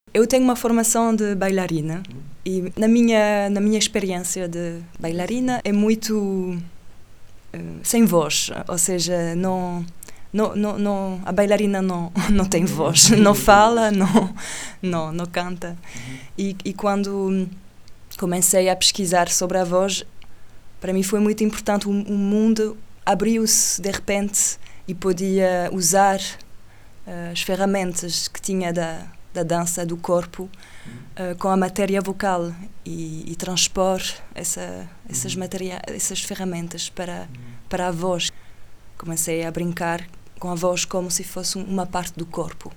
Um contínuo som [canto multifónico] grave e a sua repartição em harmónicos situa-se/-nos — quase coincide — algures na rapariga sentada, mas ela permanece imóvel.
“Oui?” Resposta e pergunta grave e aguda em formato ventríloquo — vários registos são enunciados dentro do mesmo corpo — ao passo que o próprio corpo sonda confuso a contradição dos movimentos consumados no exterior nos quais a voz não se revê.